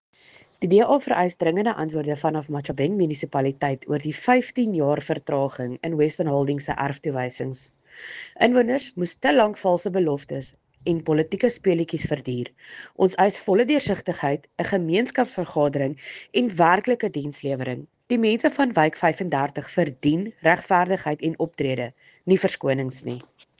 Afrikaans soundbites by Cllr Estelle Dansey and